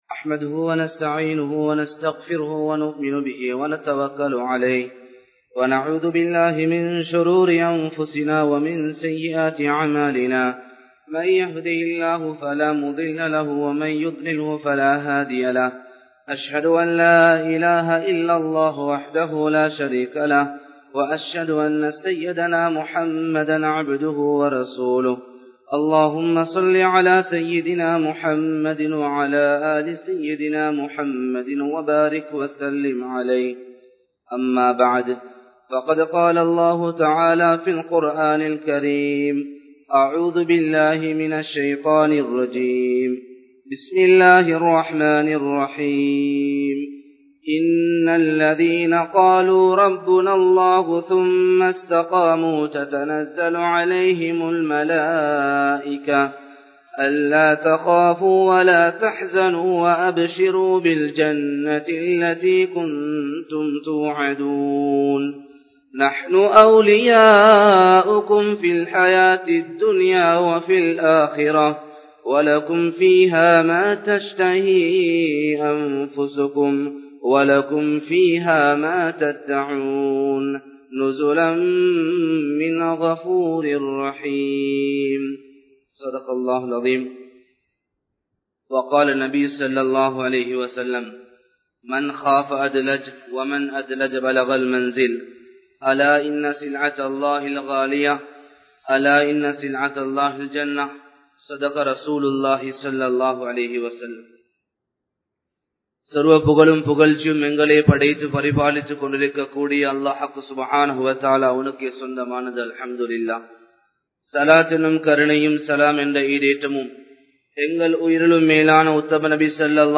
Pirchchinaihalukkaana Theervu (பிரச்சினைகளுக்கான தீர்வு) | Audio Bayans | All Ceylon Muslim Youth Community | Addalaichenai
Wellampittiya, Sedhawatte, Ar Rahmath Jumua Masjidh